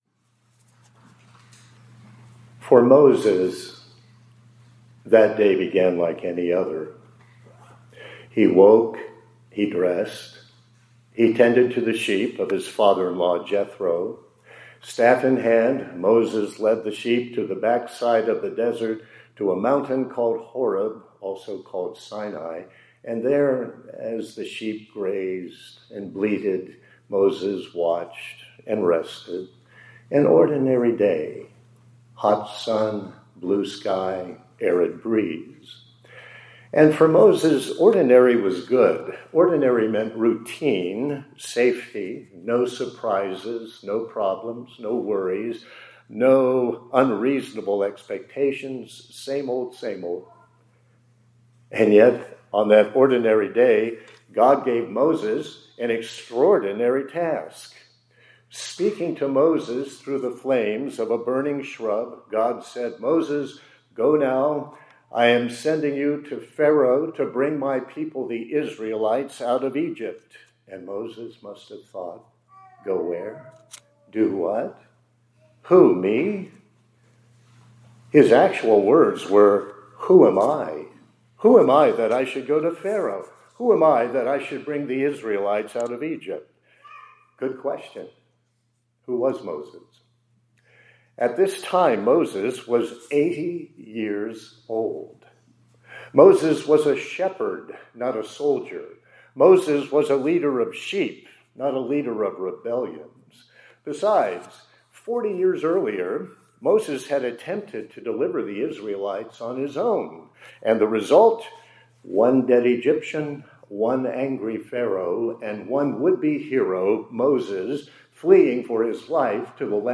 2026-03-13 ILC Chapel — “I AM”